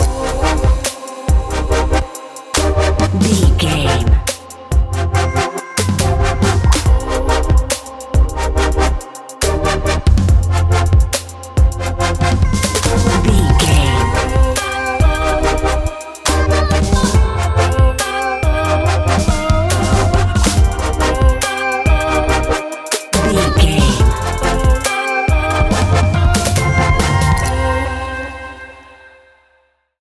Aeolian/Minor
Fast
driving
energetic
electric guitar
synthesiser
drum machine